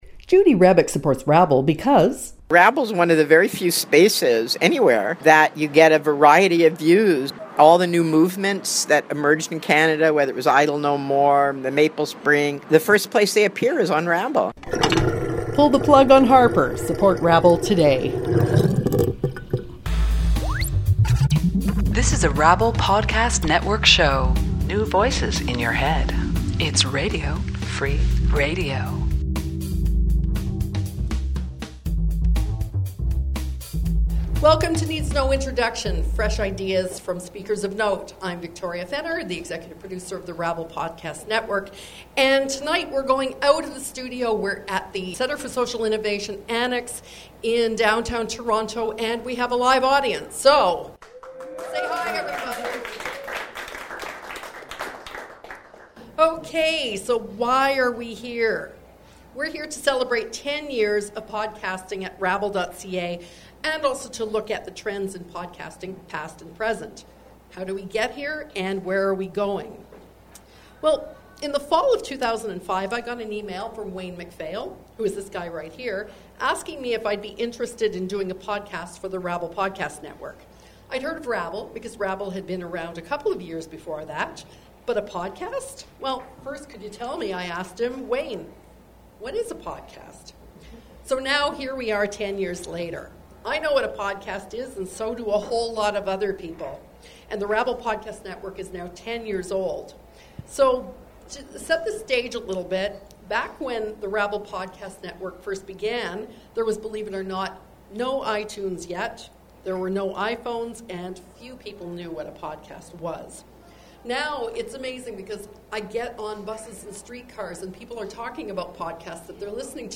Celebrating 10 years of Democracy, Technology and Podcasting September 17, 2015 | A live podcast celebrating the 10th anniversary of the rabble podcast network.